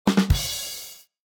Download Drum sound effect for free.
Drum